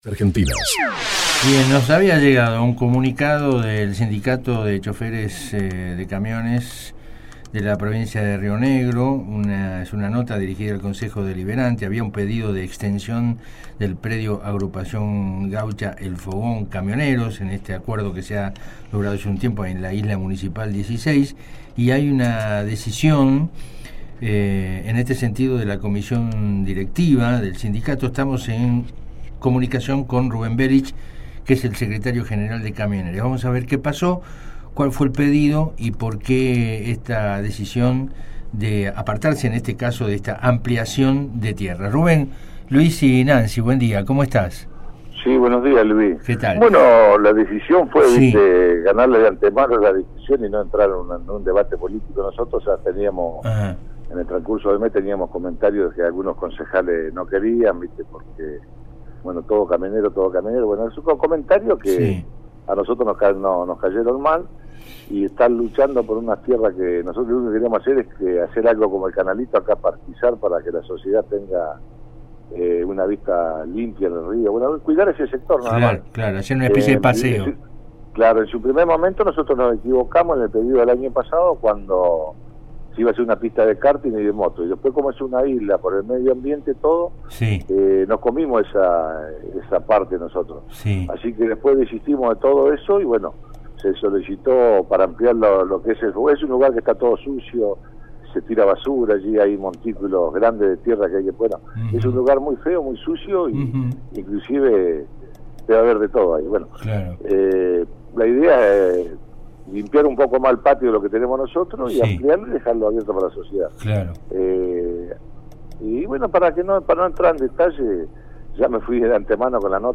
Share Facebook X Next Audio de la primera transmisión radiofónica en Argentina.